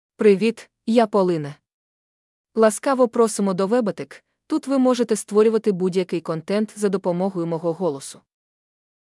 Polina — Female Ukrainian AI voice
Polina is a female AI voice for Ukrainian (Ukraine).
Voice sample
Listen to Polina's female Ukrainian voice.
Female
Polina delivers clear pronunciation with authentic Ukraine Ukrainian intonation, making your content sound professionally produced.